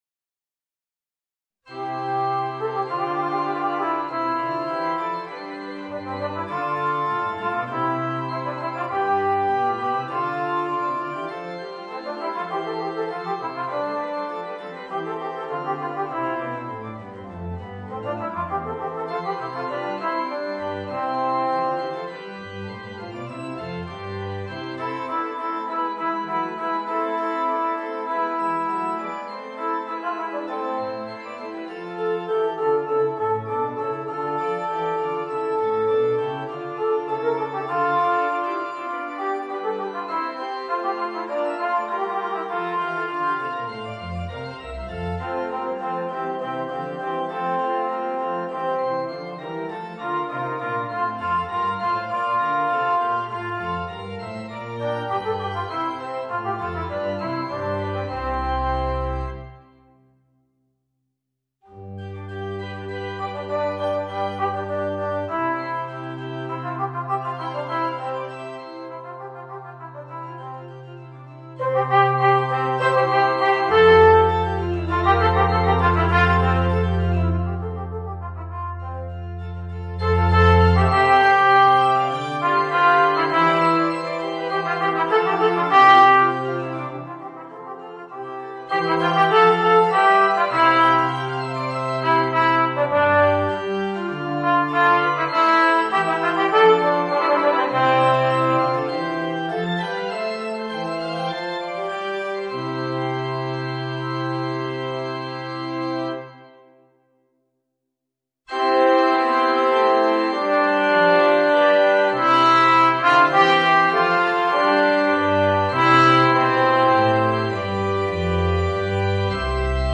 Voicing: Alto Trombone and Organ